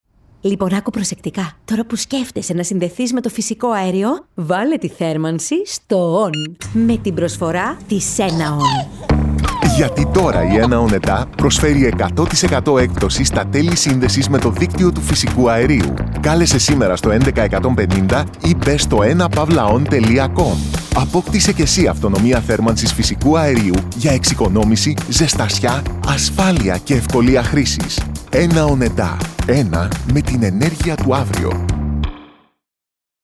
Enaon radio spot